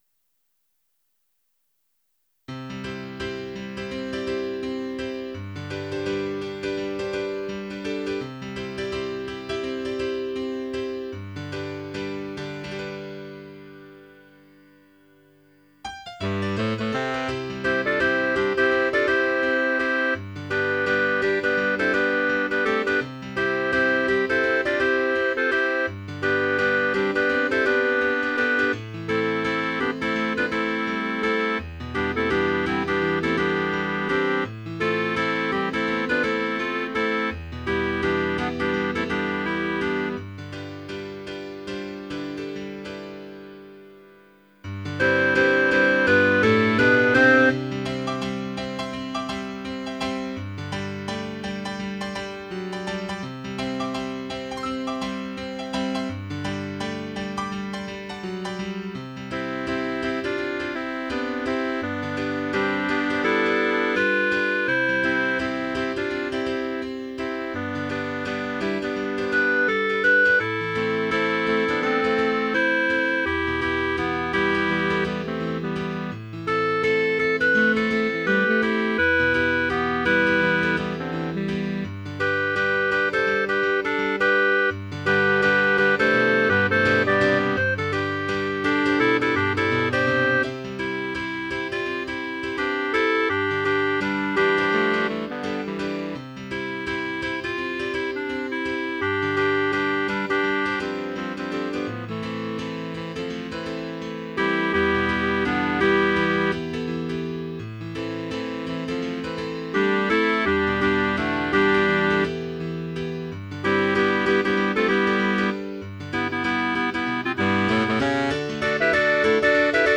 Title Them Liberals (SATB) Opus # 182 Year 0000 Duration 00:02:41 Self-Rating 4 Description With tongue firmly in cheek. mp3 download wav download Files: wav mp3 Tags: Piano, Choral Plays: 1641 Likes: 0